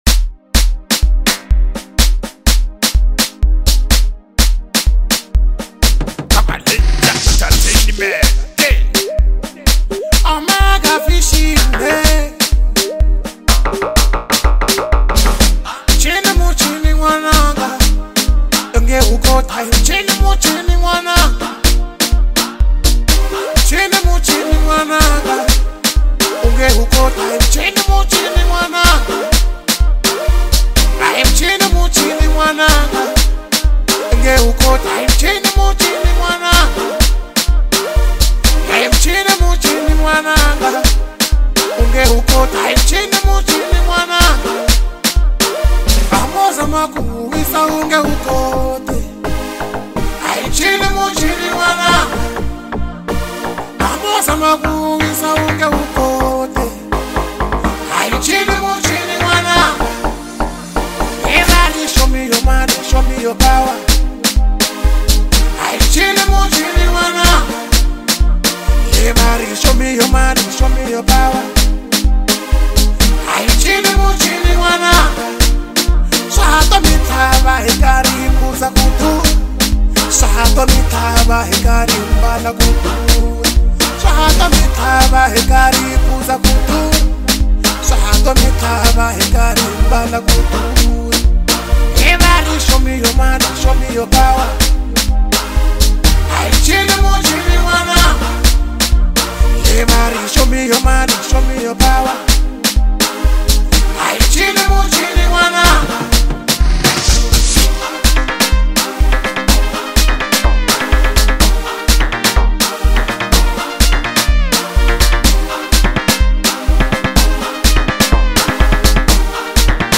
high energy track